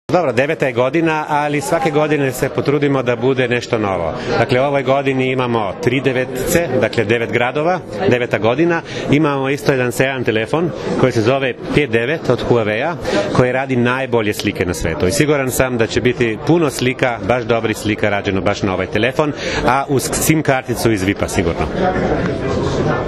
U beogradskom klubu “Lemon Čili” danas je svečano najavljen 9. „Vip Beach Masters 2016. – Prvenstvo Srbije u odbojci na pesku”, odnosno 5. „Vip Beach Volley Liga“.
IZJAVA